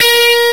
Index of /m8-backup/M8/Samples/Fairlight CMI/IIX/GUITARS
FUZZGTR.WAV